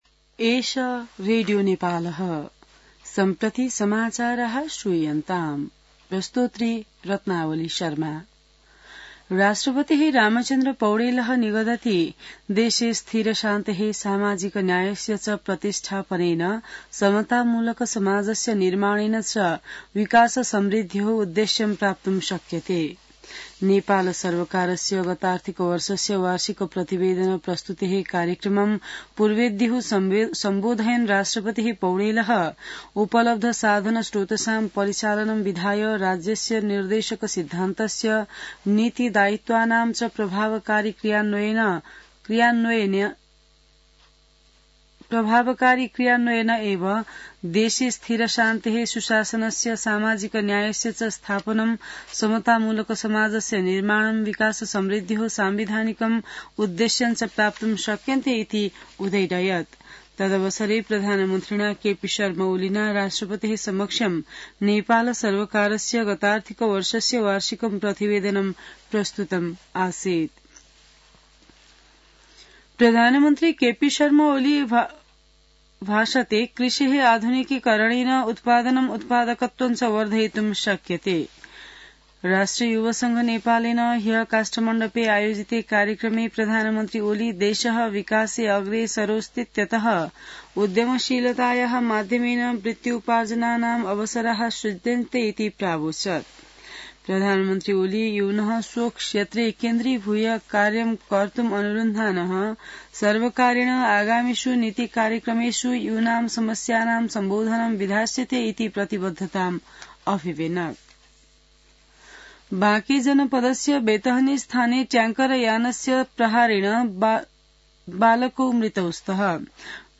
संस्कृत समाचार : ११ चैत , २०८१